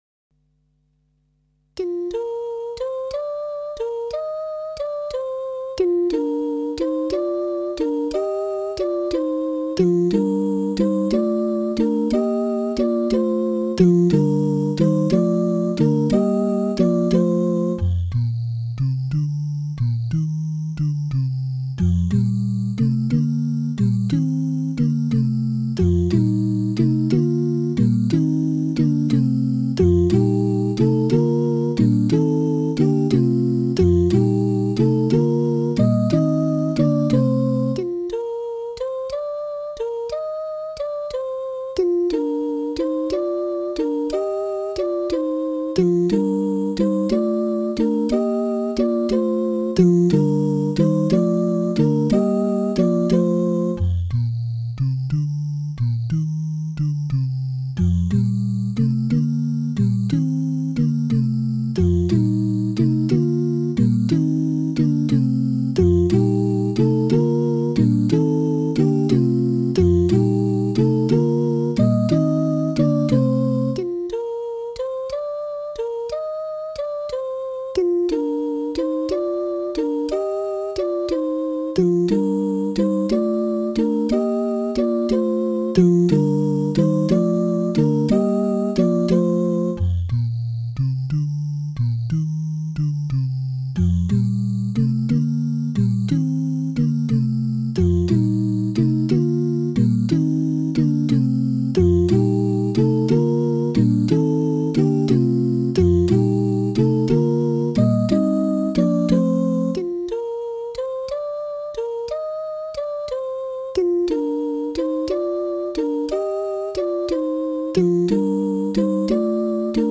This Precious Night (Processional) Choir (Words)